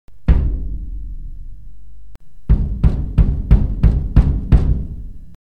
Suur trumm
Suur trumm on basspill.
Bass_drum.ogg.mp3